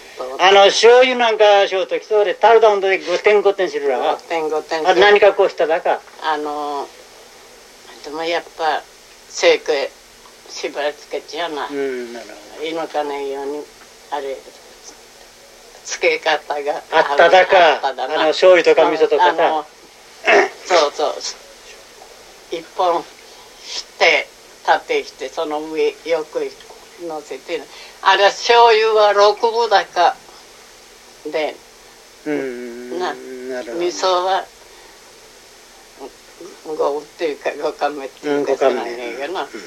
あまりに興味深い話なので、少々長めに引用してしまいましたが、聞いていただきたいのは「みそ」の部分です。
最初の「みそ（とか）」のアクセントは「高低」、次の「みそ（は）」のアクセントは「低高」になっています。
このように、同一の話し手であっても、アクセントが安定しないのが、井川方言の特徴です。